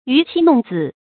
娛妻弄子 注音： ㄧㄩˊ ㄑㄧ ㄋㄨㄙˋ ㄗㄧˇ 讀音讀法： 意思解釋： 和妻子兒女玩樂。